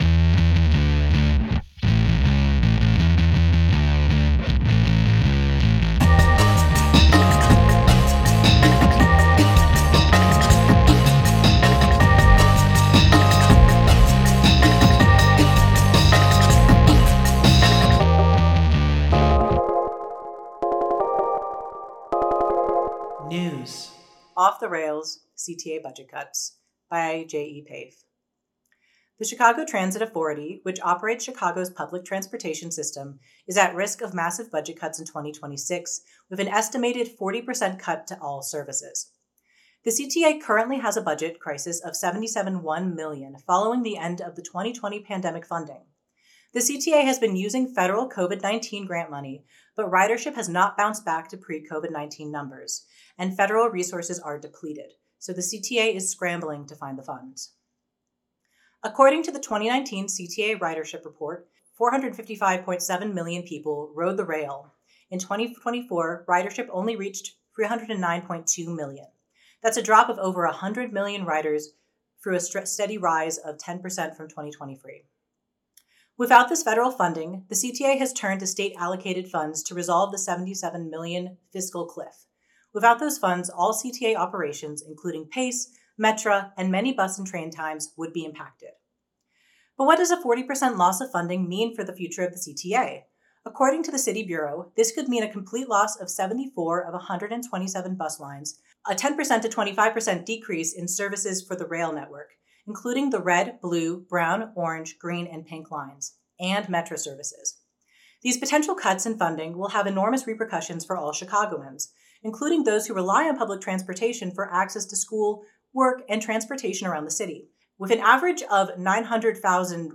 The text of this article was read aloud and recorded for your greater accessibility and viewing pleasure: